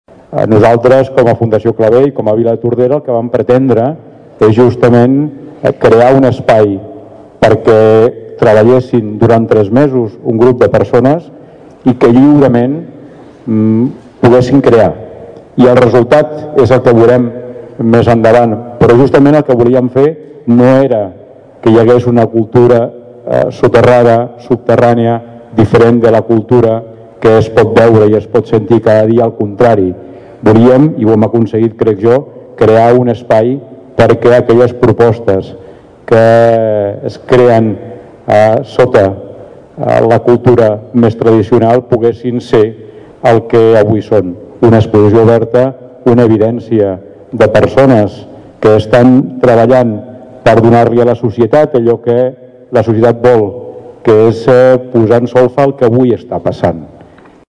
L’exposició presenta cinc espais diferents, on s’hi troben recollits els treballs realitzats per cadascuna de les cinc participants. Joan Carles Garcia, alcalde de Tordera, ha destacat la importància cultural que té per al municipi la residència artística.